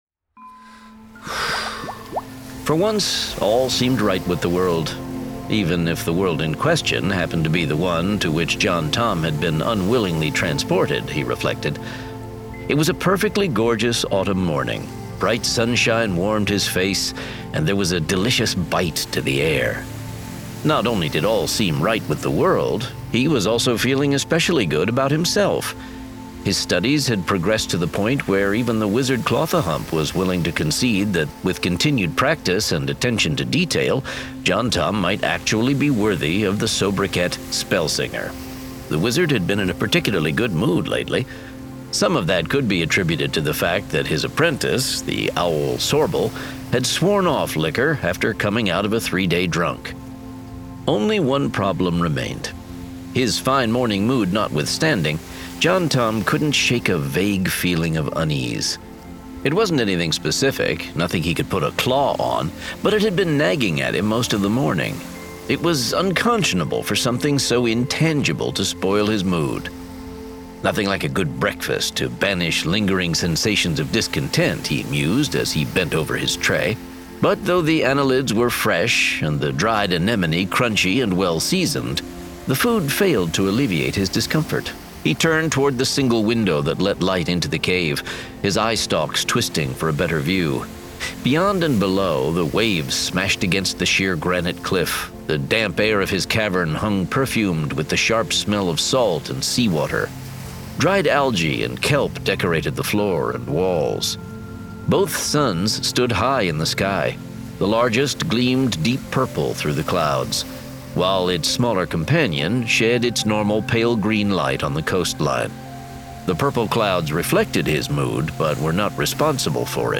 Spellsinger 5: The Paths of the Perambulator [Dramatized Adaptation]